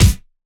Kicks
take care kick 2 ~.wav